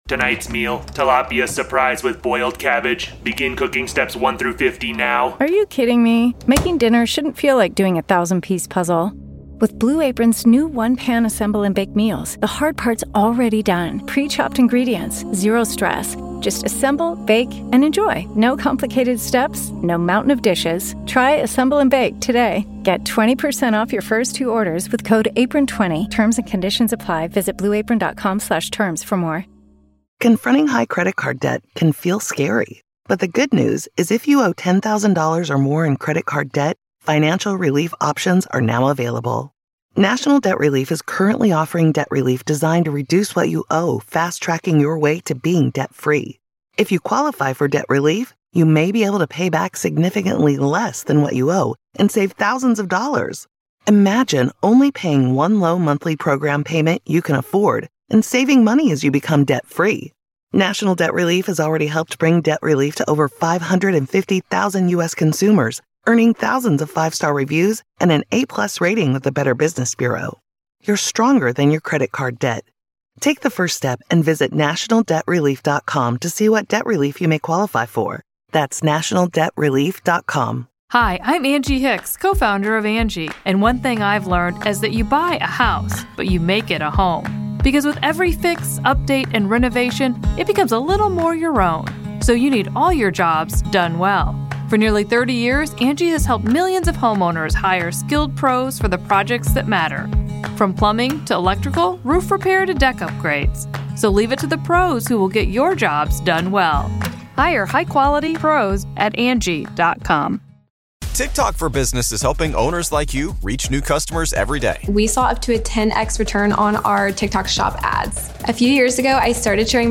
sit down for a wide-ranging, honest conversation recorded during the Christmas season